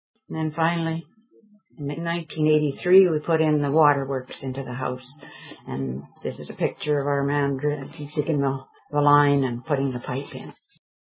Water line - Sound Clip